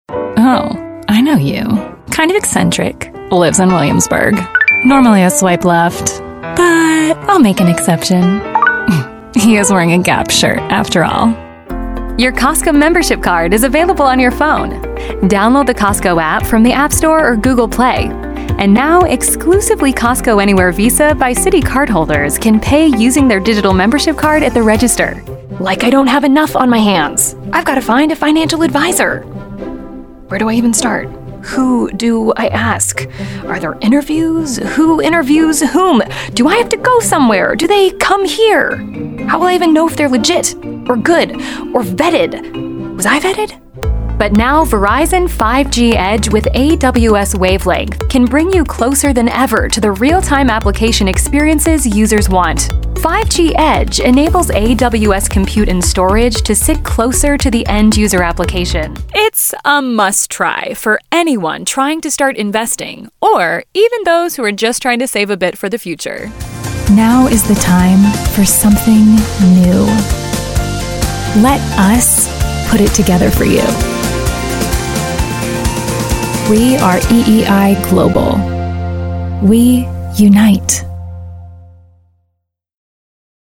Compilation Demo
English - Midwestern U.S. English
Midwest USA, General American
Young Adult
Middle Aged